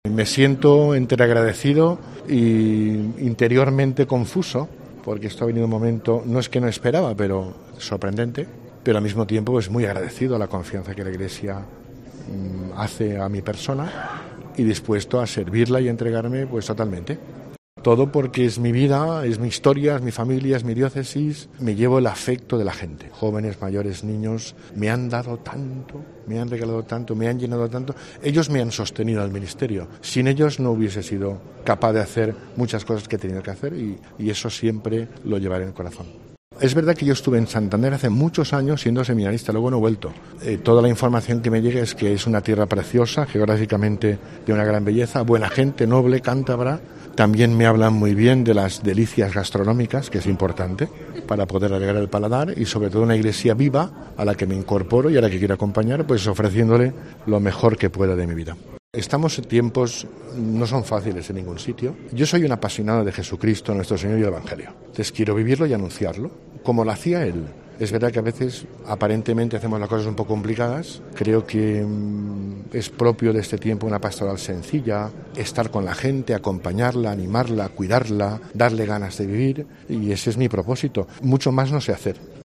Reflexiones de Don Arturo Ros tras su nombramiento como Obispo de Santander